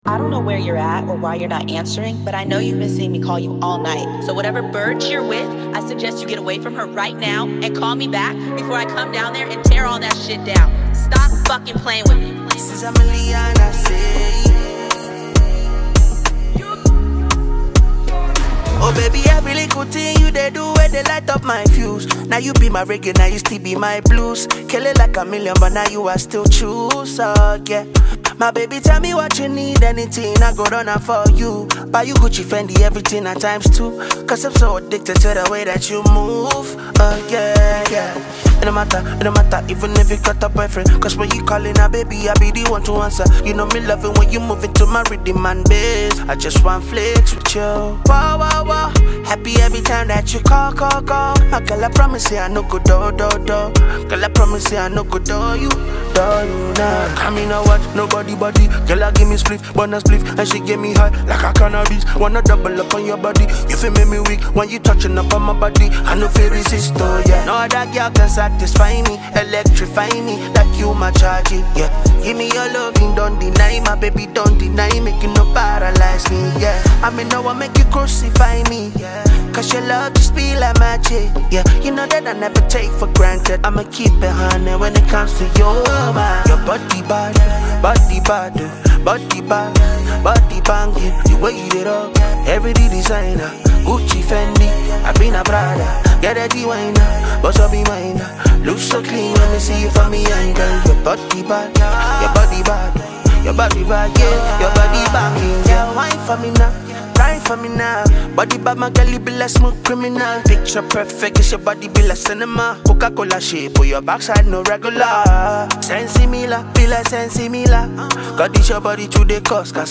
Nigerian singer and songwriter
The song’s production is minimal yet engaging
keeping the tempo lively and dance-ready